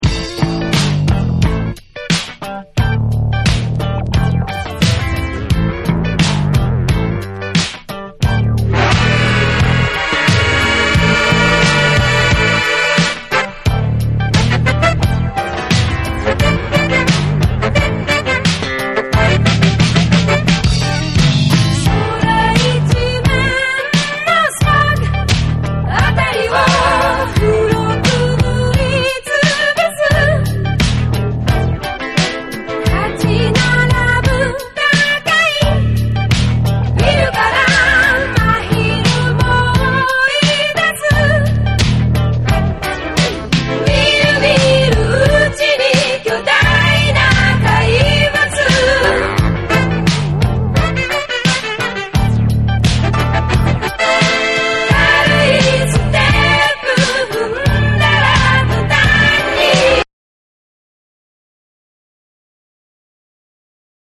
ソウルフルなヴォーカルで定評のあるポップシンガー